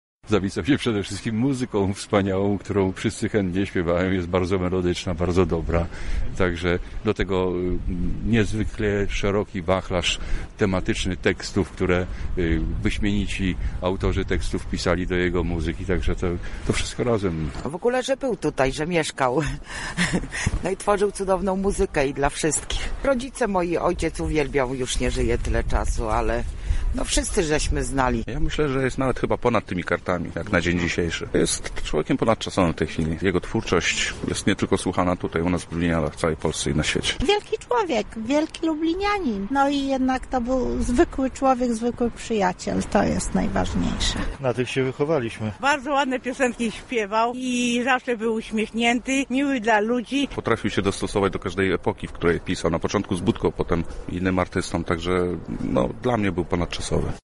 Spytaliśmy także uczestników pogrzebu jak ważną postacią dla nich był niedawno zmarły lider Budki Suflera:
Sonda